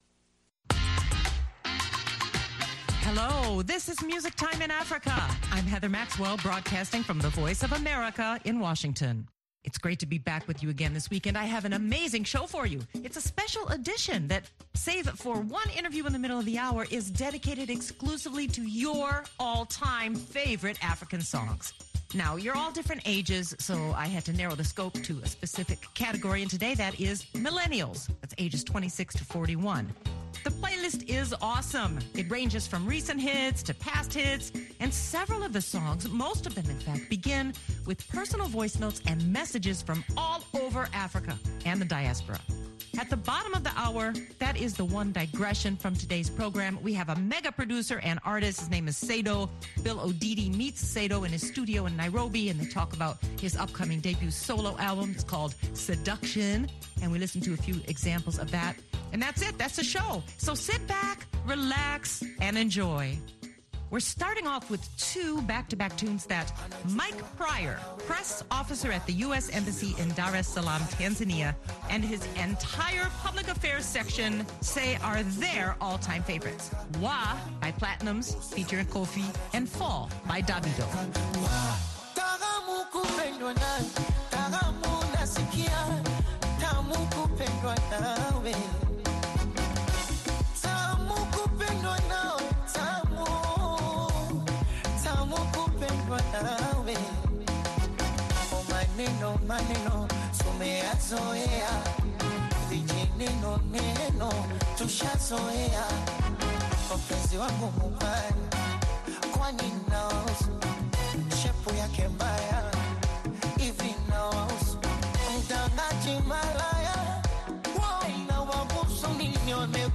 And then it’s back to more African millennial hits that will groove and sway you to the end of the hour.